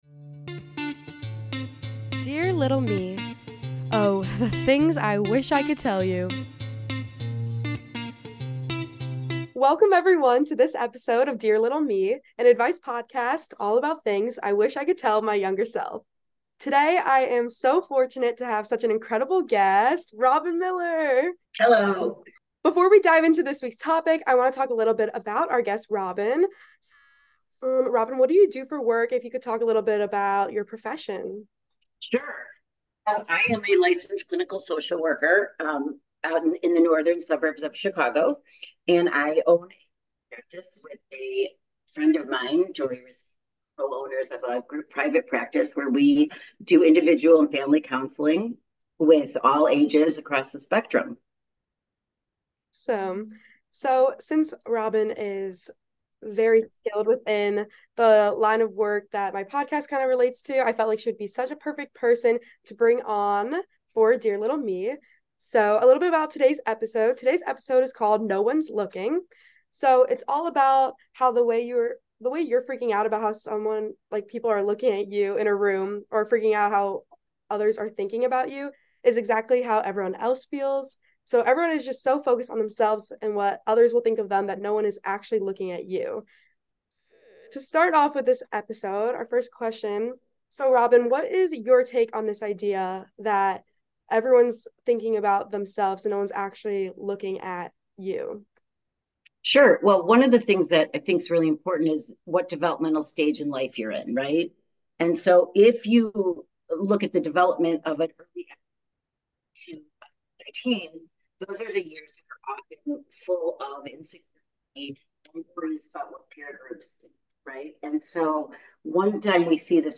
52- 1:39: episode introduction in relation to guest and interview begins